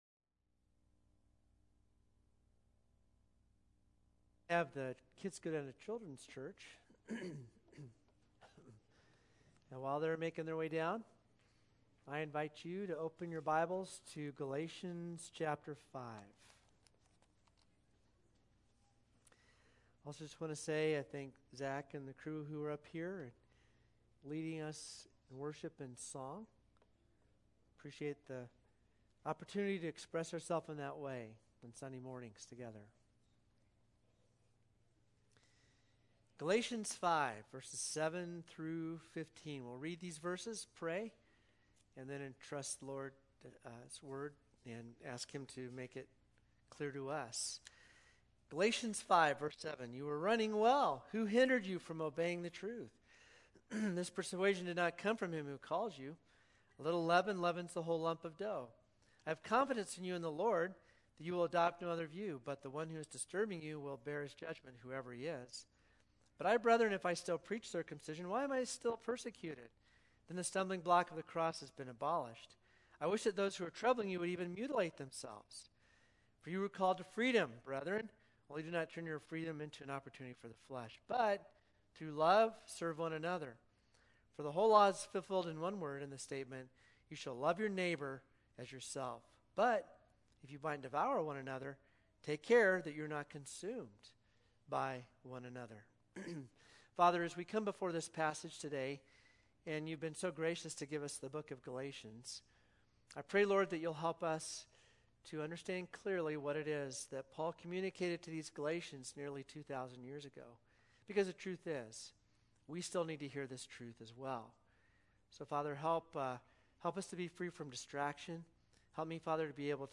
10-3-21-sermon.mp3